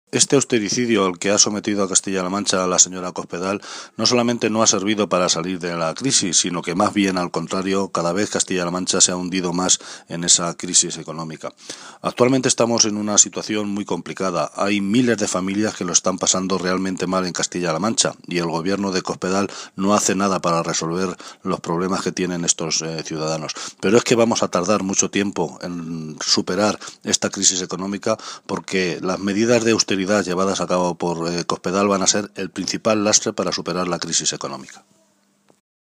José Luis Martínez Guijarro
Cortes de audio de la rueda de prensa